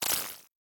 Hi Tech Alert 8.wav